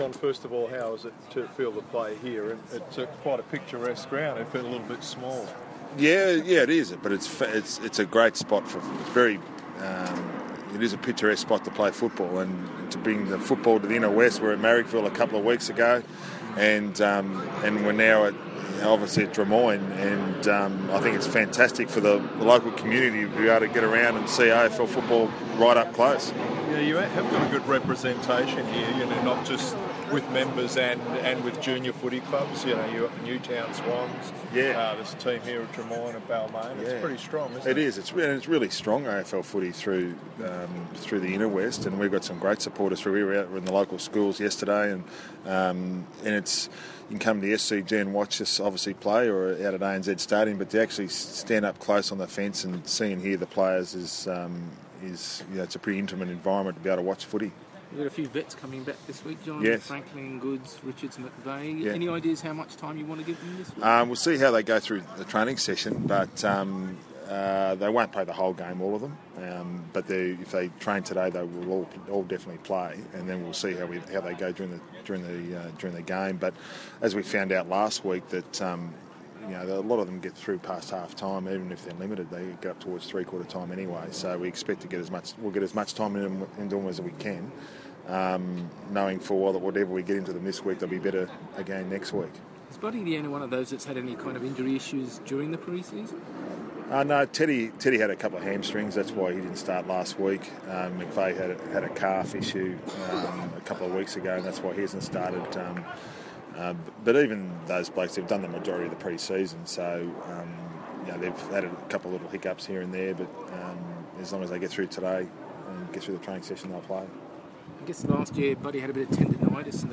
Coach John Longmire speaks to the media ahead of Sunday's clash against Fremantle.